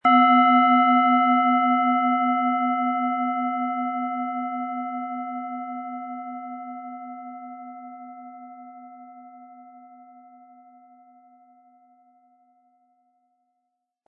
Planetenschale® antik Stärke Dein Selbstbewusstsein & In die Mitte kommen mit Sonne, Ø 10,6 cm, inkl. Klöppel
Diese antike Planetenschale® schwingt im Ton der Sonne – kraftvoll und warm.
Ihr Klang bleibt dabei klar, harmonisch und lebendig – durchdrungen von der Kraft der Sonne.
Unter dem Artikel-Bild finden Sie den Original-Klang dieser Schale im Audio-Player - Jetzt reinhören.
MaterialBronze